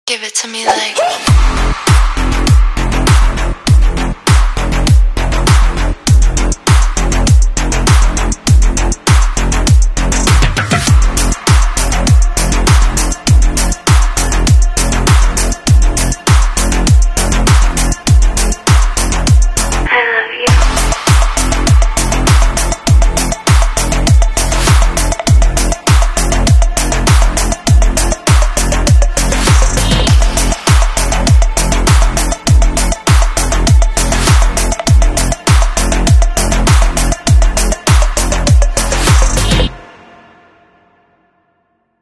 Cek sound speaker d&b dan sound effects free download
4 speaker CCL8 dan 4 speaker CCL - SUB .